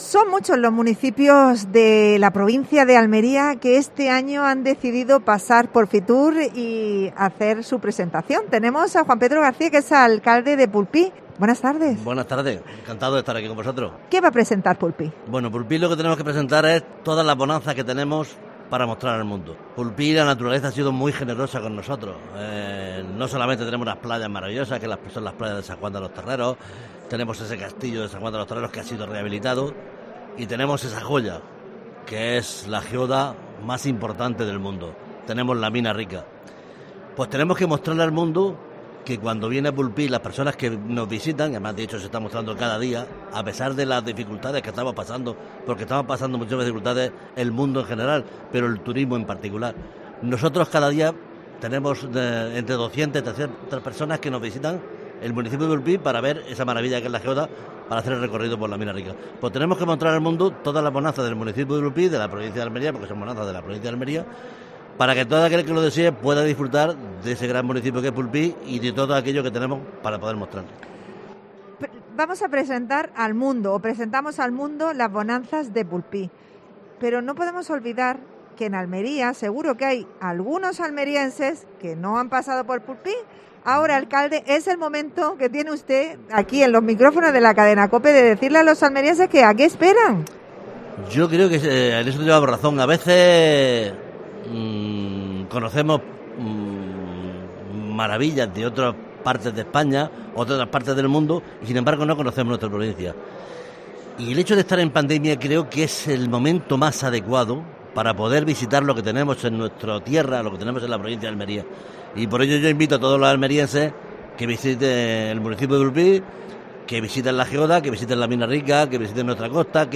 Un municipio con la Geoda más importante de Europa y con otros muchos encantos es para visitarlo. El alcalde de Pulpí invita a todos para que lo comprueben.